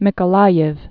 (mĭkə-läyĭv, -yē) or Ni·ko·la·yev (nĭkə-läyəf, nyĭkə-)